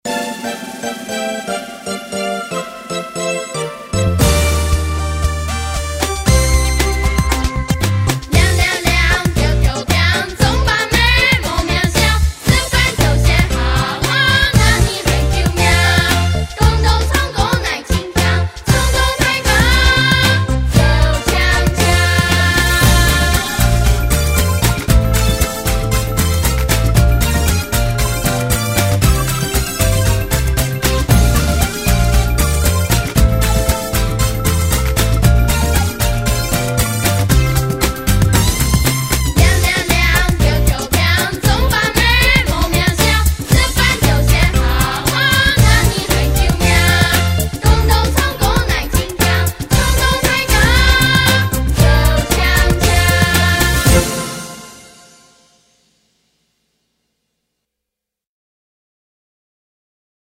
鈴鈴鈴(完整演唱版) | 新北市客家文化典藏資料庫